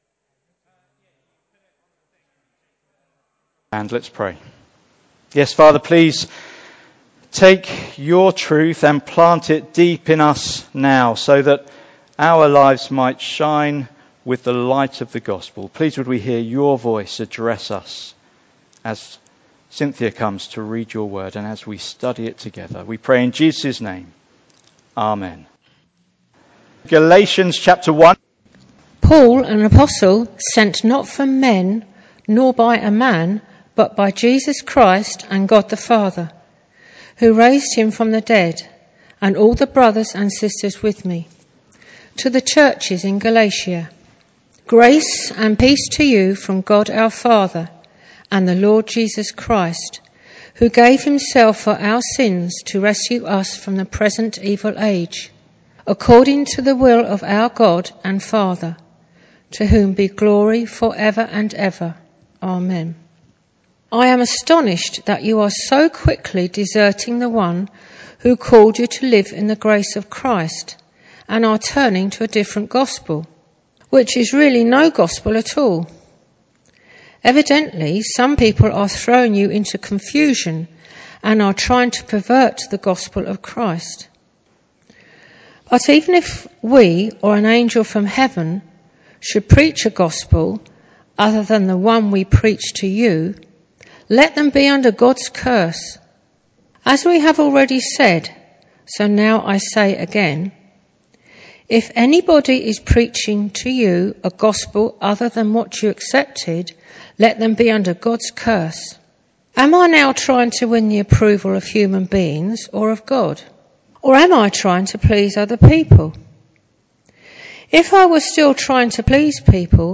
Passage: Galatians 1:1-24 Service Type: Sunday Morning